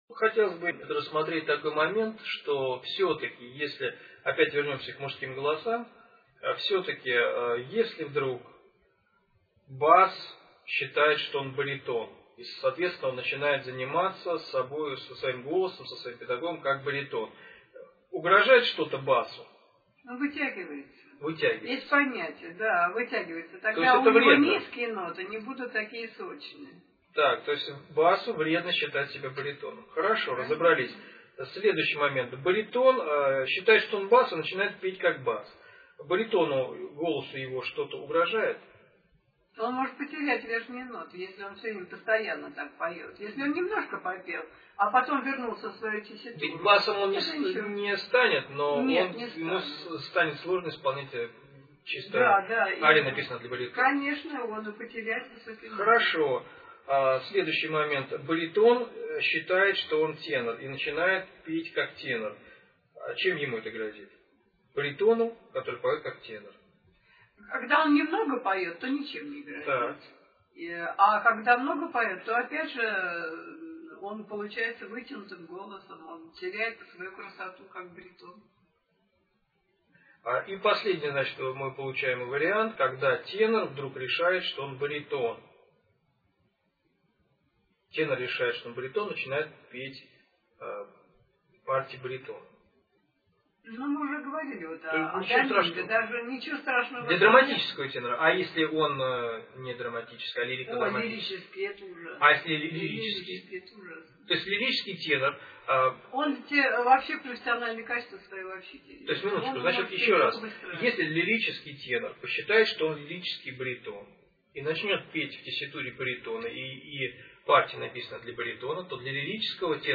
Беседуют педагог-вокалист